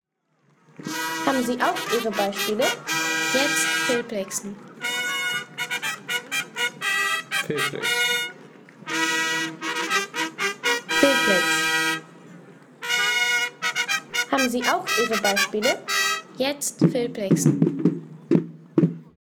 Mittelalterliche Trompete
Inszenierter Auftritt des Feldzuges bei einem Ritterturnier.